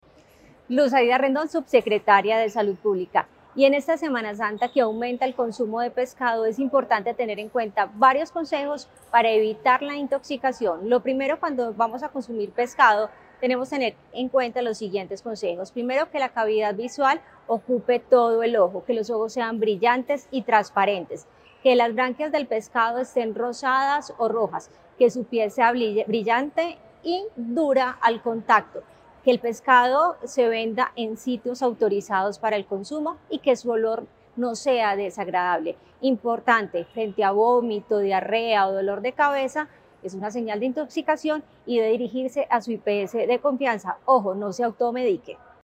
Declaraciones subsecretaria de Salud Pública, Luz Aida Rendón.
Declaraciones-subsecretaria-de-Salud-Publica-Luz-Aida-Rendon..mp3